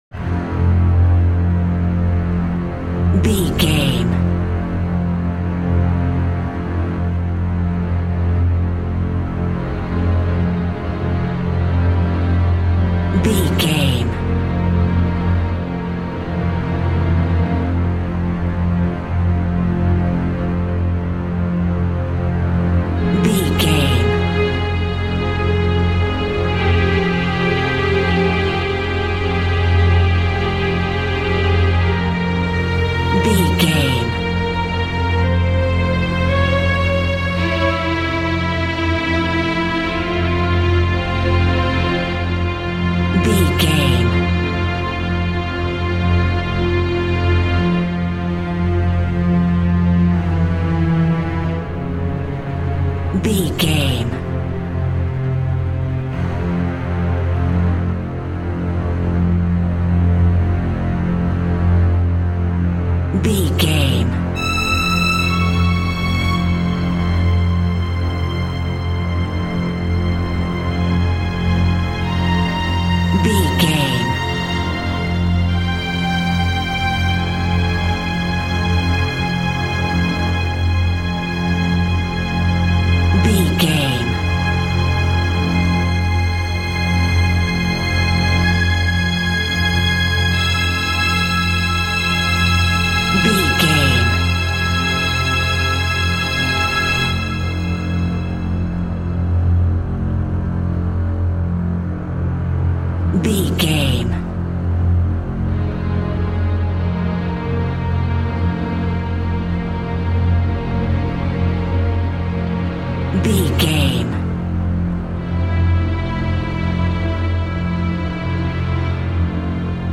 Aeolian/Minor
scary
tension
ominous
dark
suspense
haunting
eerie
synth
ambience
pads